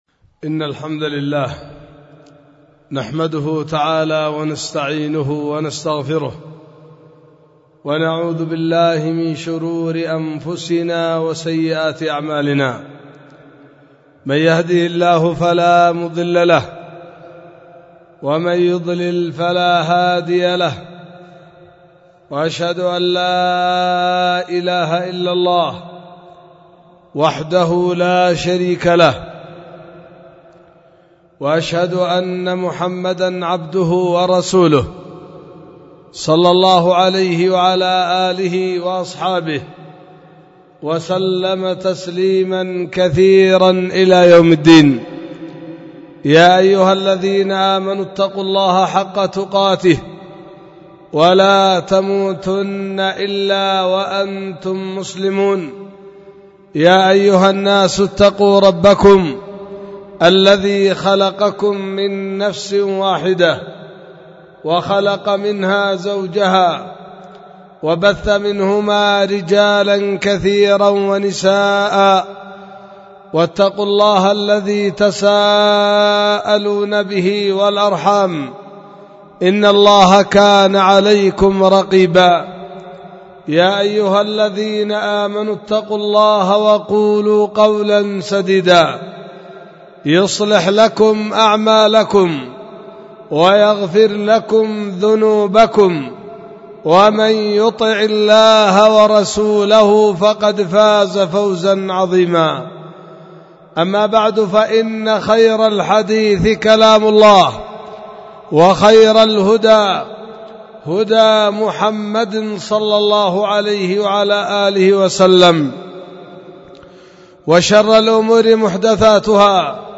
خطبة
في دار الحديث بوادي بنا – السدة – إب – اليمن